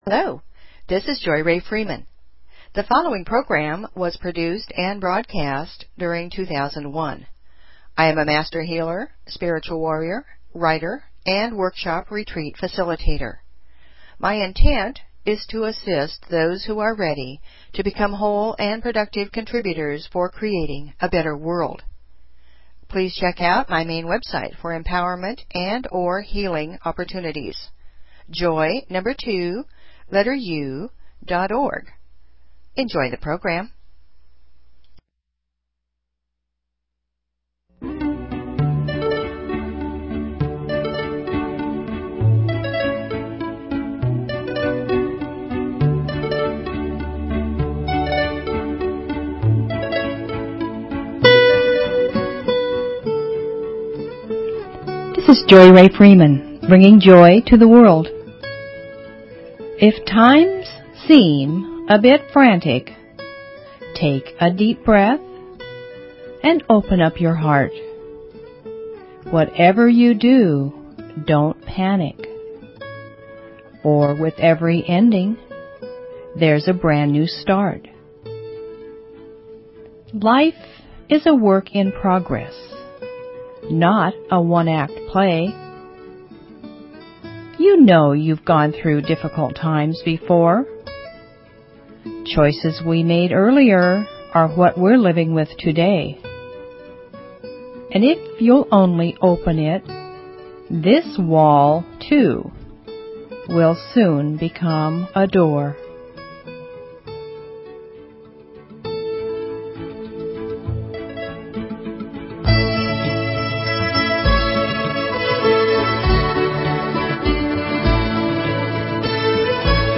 Talk Show Episode, Audio Podcast, Joy_To_The_World and Courtesy of BBS Radio on , show guests , about , categorized as
(2001) Music, poetry, affirmations, stories, inspiration . . .
The Communicator Awards International Award Winning Radio Program A unique program format - designed to inspire and transform